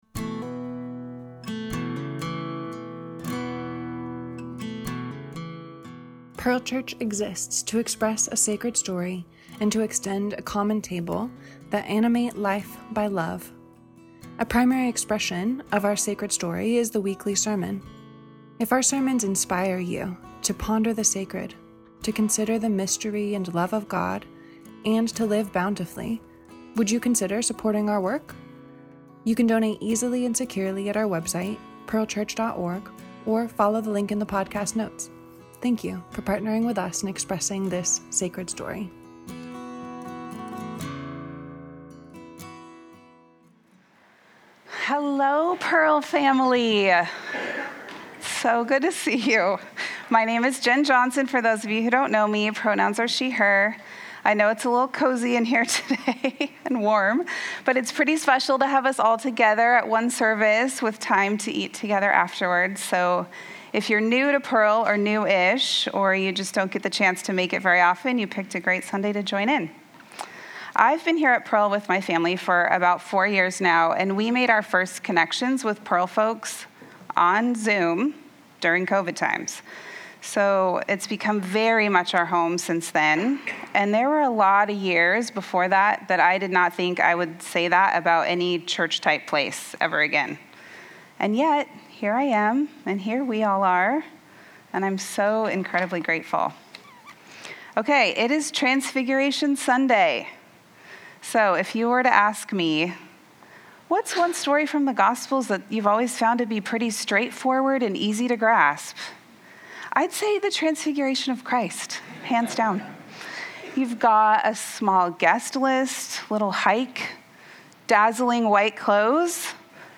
A primary expression of our sacred story is the weekly sermon.